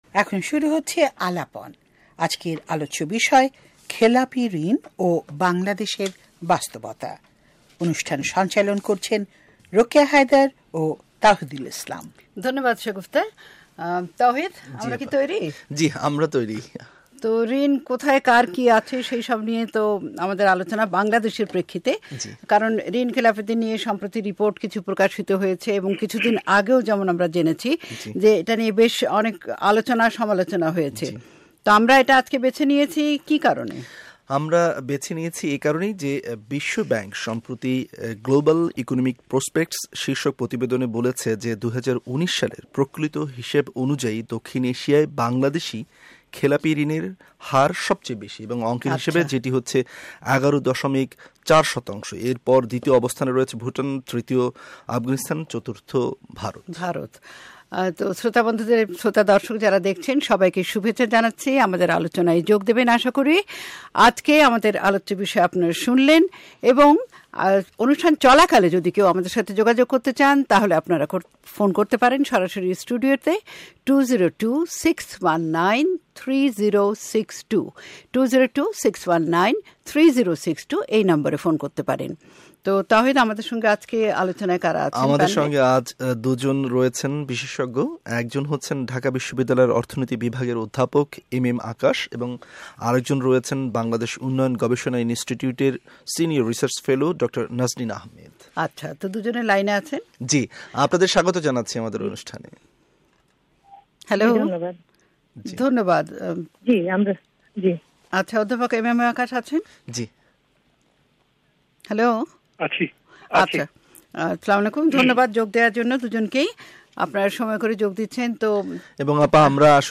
এছাড়াও ছিল অর্থমন্ত্রী আ হ ম মুস্তফা কামালের সম্প্রতি দেওয়া একটি সাক্ষাৎকারের একটি অংশ